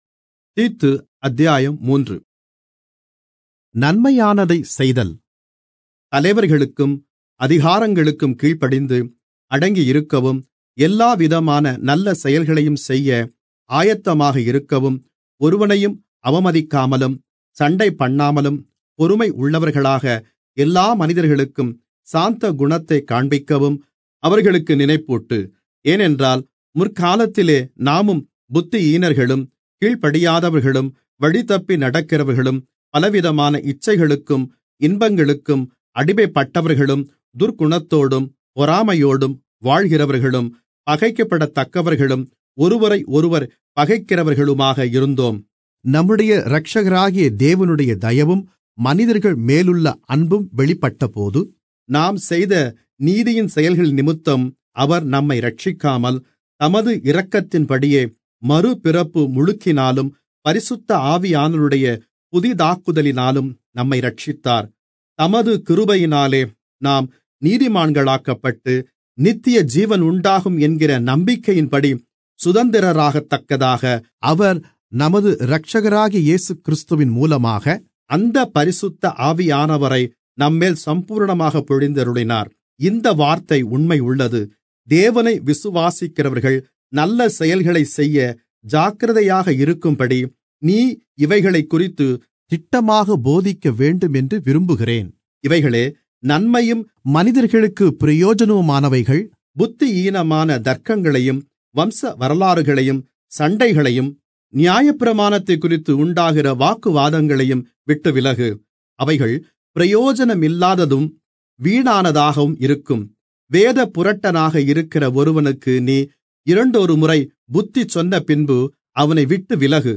Tamil Audio Bible - Titus 2 in Irvta bible version